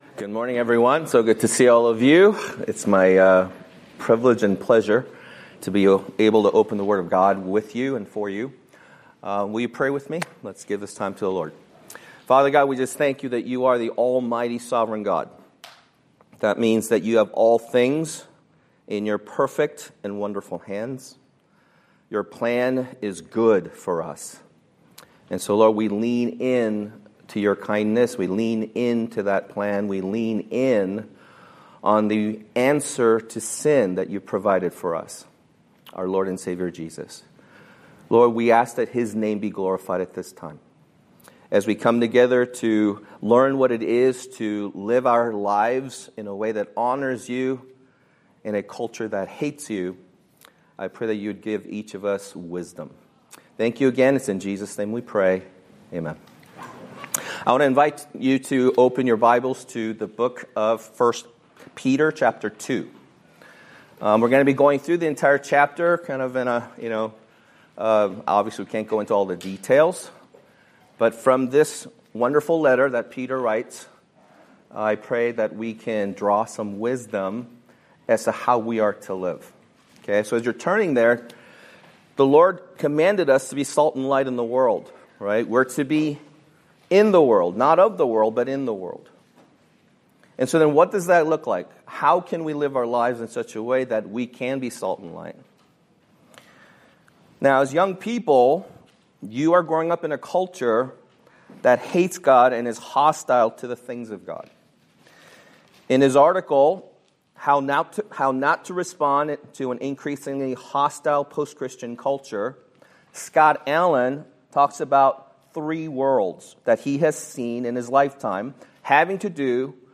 Date: Jun 30, 2024 Series: Search and Rescue (Summer Camp 2024) Grouping: Student Ministries More: Download MP3